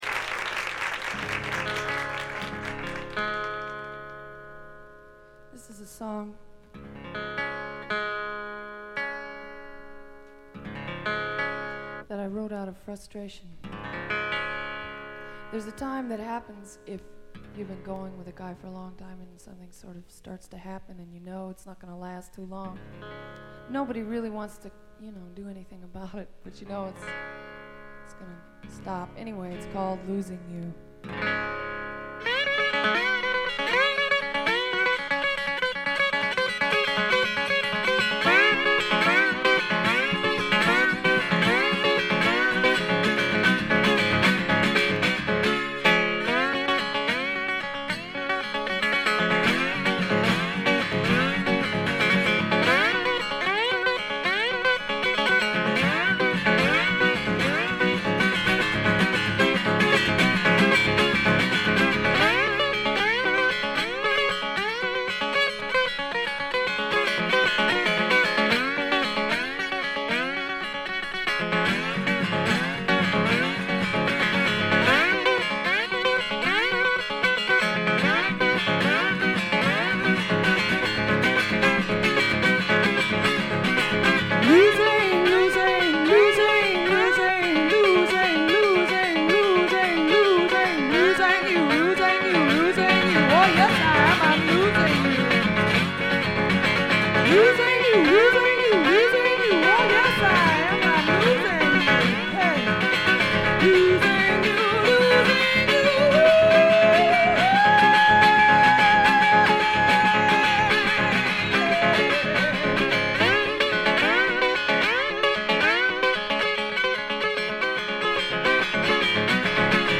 部分試聴ですがほとんどノイズ感無し。
試聴曲は現品からの取り込み音源です。
Side A Recorded Live at The Bitter End, N.Y.C.